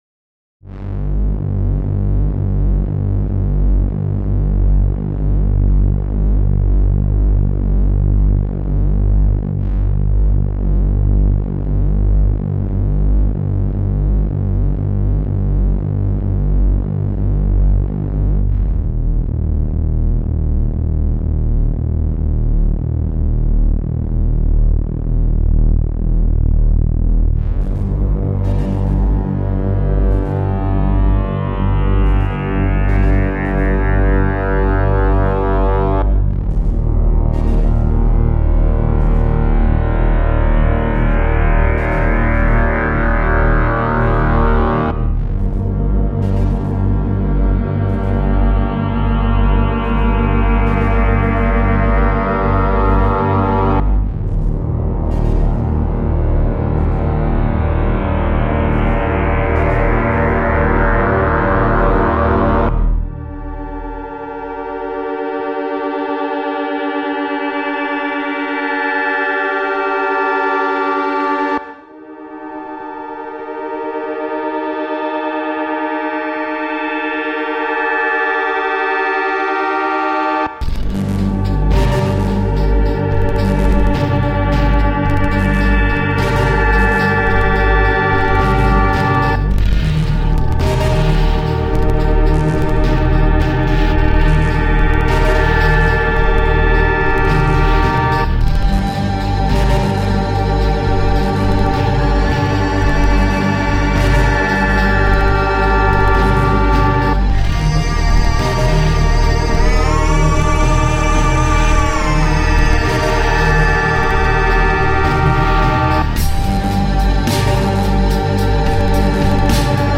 harmonic powerhouse which drifts away from the theme a bit sometimes but remains solid and moody.
heavy track with good sound design.